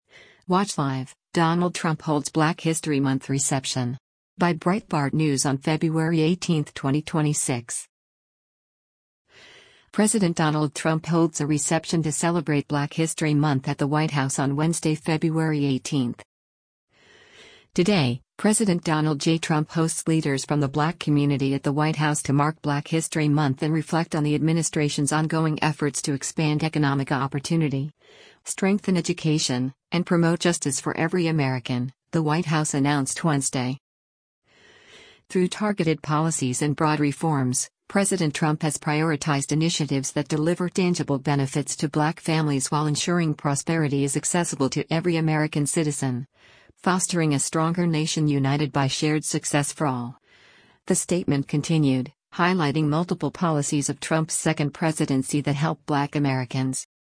President Donald Trump holds a reception to celebrate Black History Month at the White House on Wednesday, February 18.